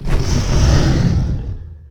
CosmicRageSounds / ogg / general / combat / creatures / dragon / he / attack1.ogg